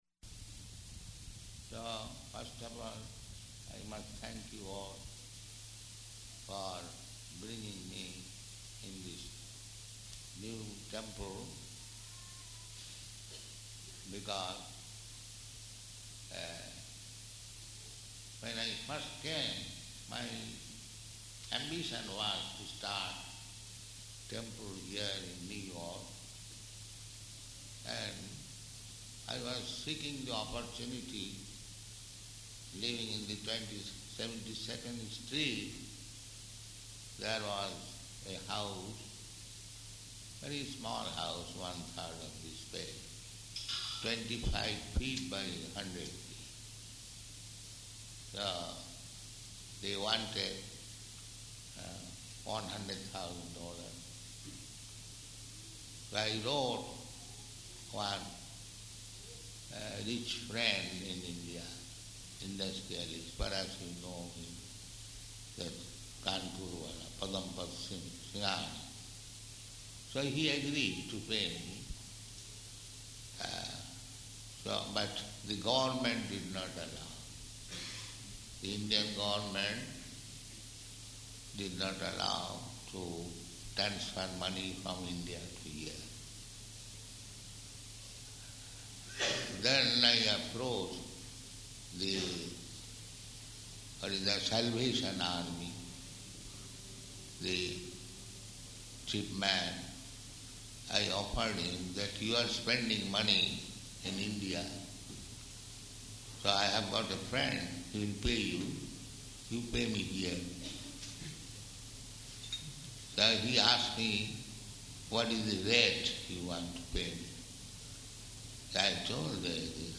Arrival Address
Type: Lectures and Addresses
Location: New York